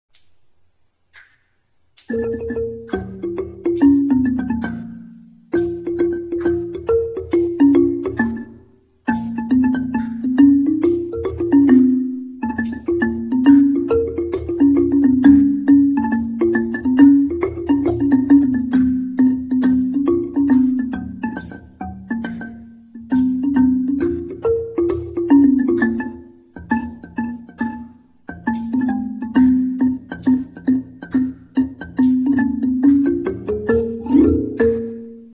Khryang Tii : hit instruments (made of wood)
Ranad Thum
The keys are made of similar kinds of wood, but they are longer and wider.